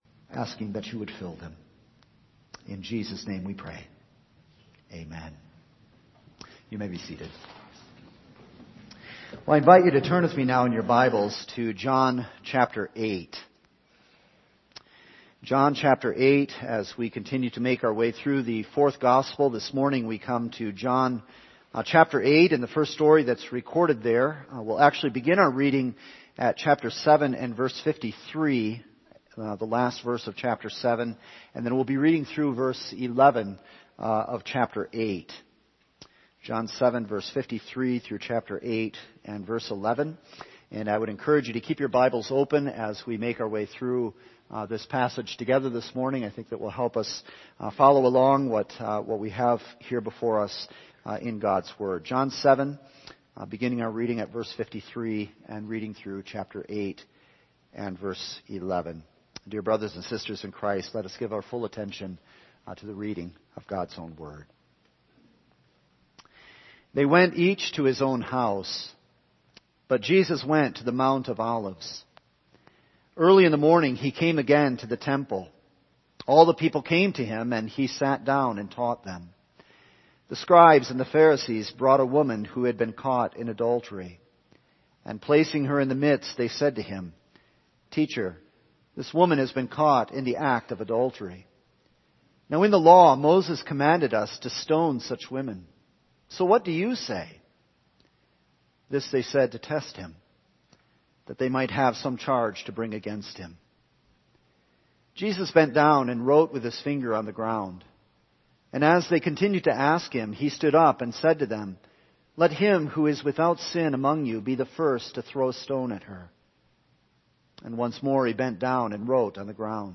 All Sermons A Wretch and Mercy November 21